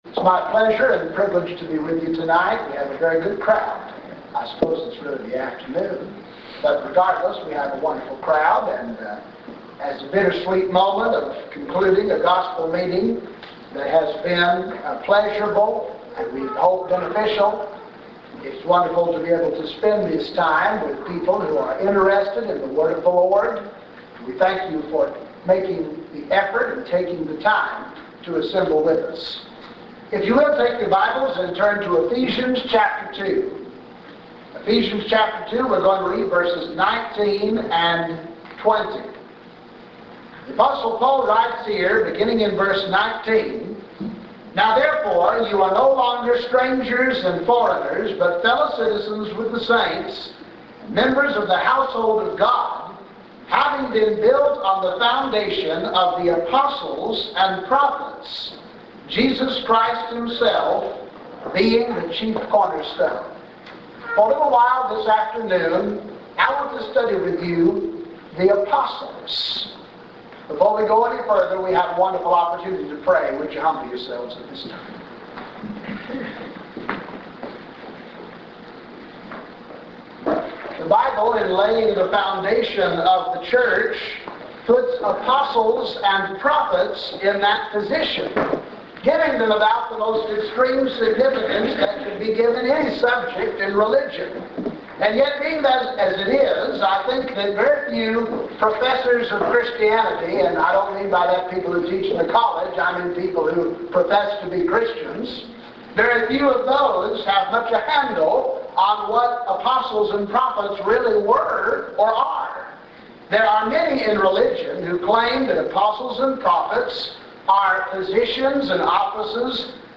The Apostles – a sermon